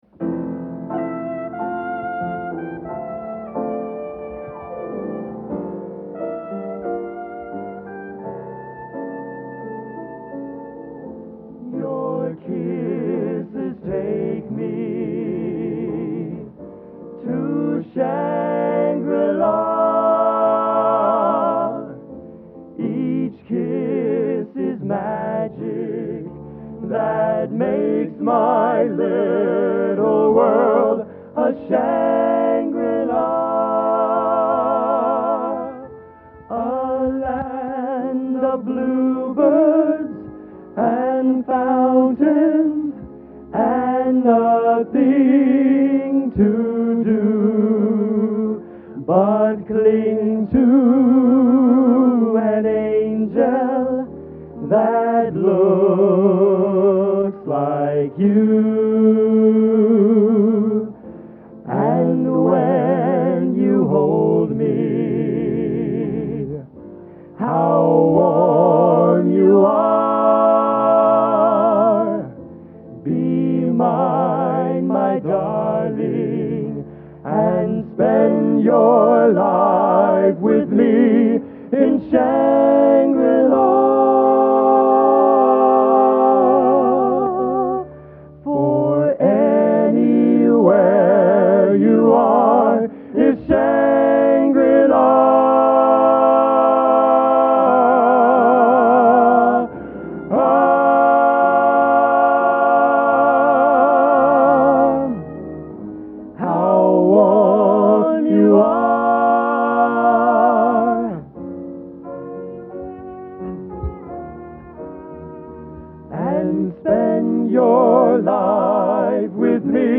Location: West Lafayette, Indiana